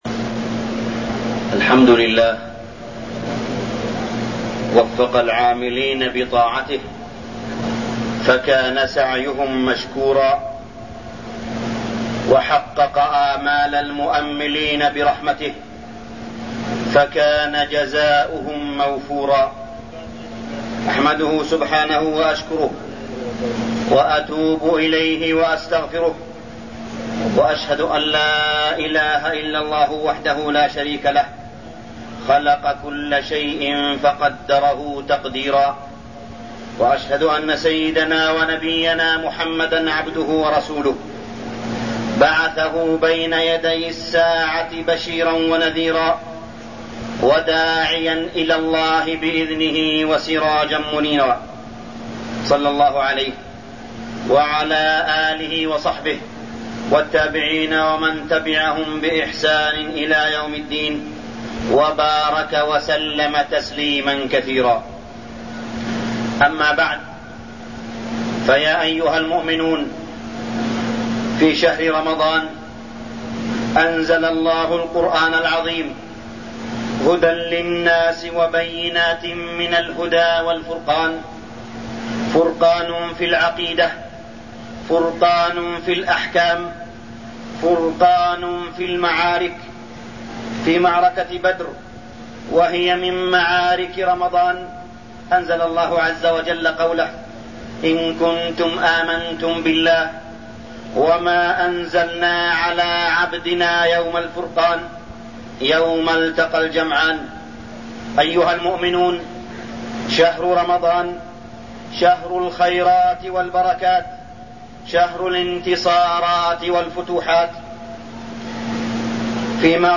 تاريخ النشر ٢٨ محرم ١٤٠٩ هـ المكان: المسجد الحرام الشيخ: معالي الشيخ أ.د. صالح بن عبدالله بن حميد معالي الشيخ أ.د. صالح بن عبدالله بن حميد أهمية الصلاة